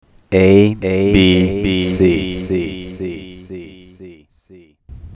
delay and echo effect:
abcdelay128.mp3